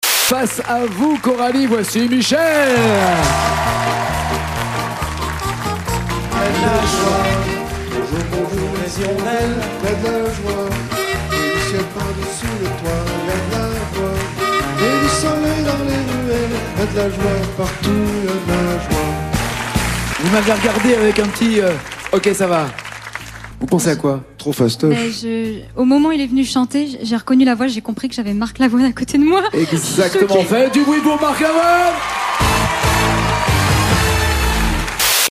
Alors qu’il a modifié sa voix tout au long de l’émission, ils ont fini par s’affronter tous les deux pour une battle finale sur le titre : « Seul définitivement ».